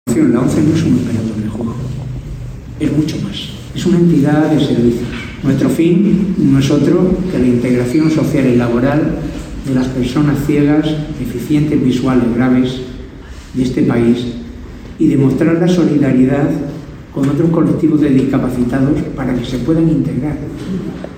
Mesa inaugural de las jornadas de Fejar